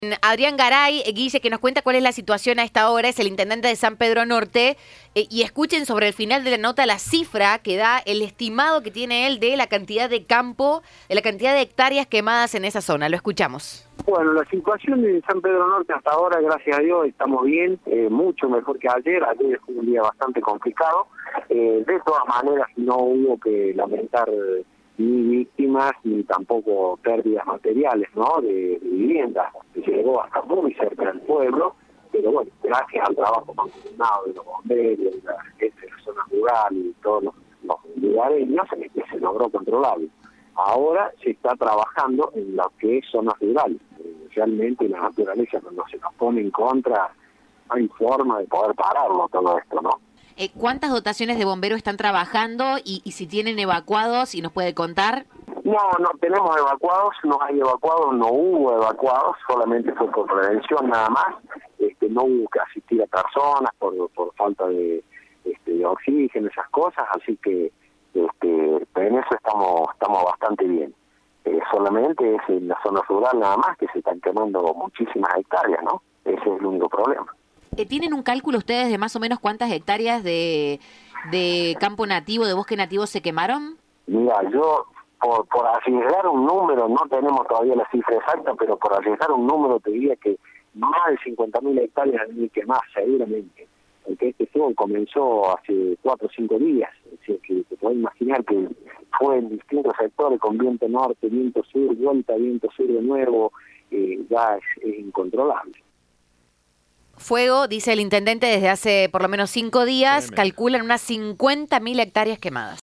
Adrián Garay, intendente de San Pedro Norte, dijo a Cadena 3 que se quemaron alrededor de 50 mil hectáreas de bosque nativo en esa localidad.
Informe